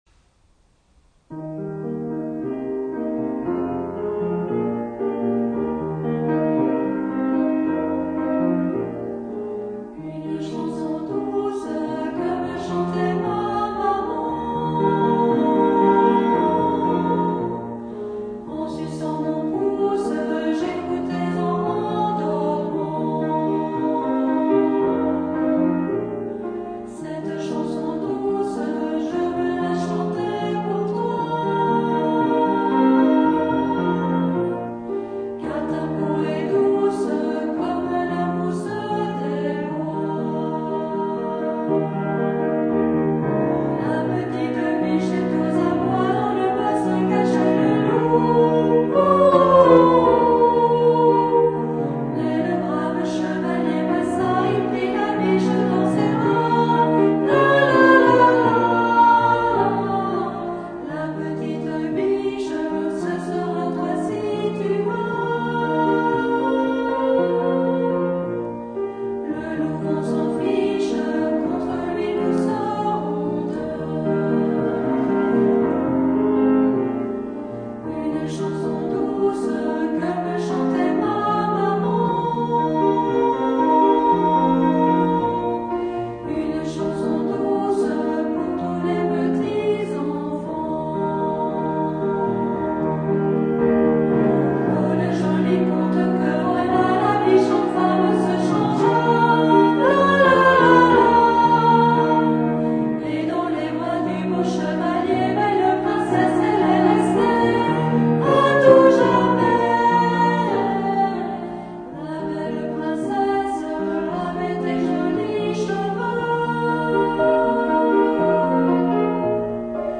Chant à 1 voix
Version chantée :